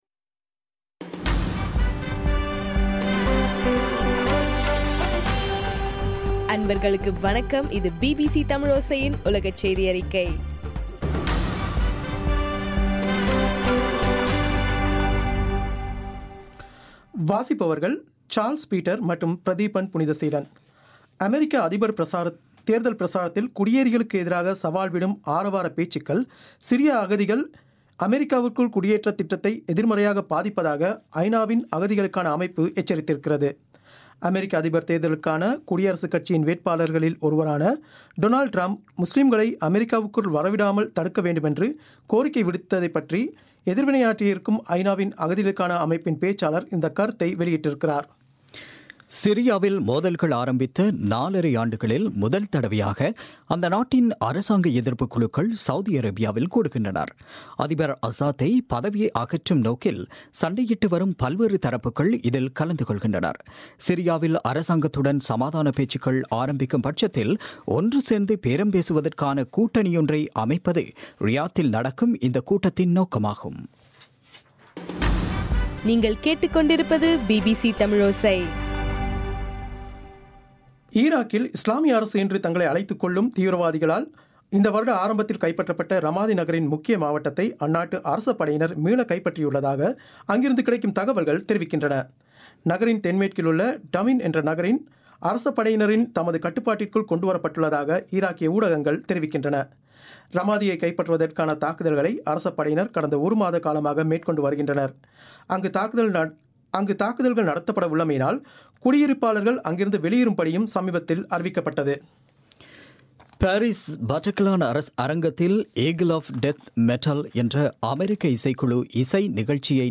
இன்றைய ( டிசம்பர் 8) பிபிசி தமிழ் செய்தியறிக்கை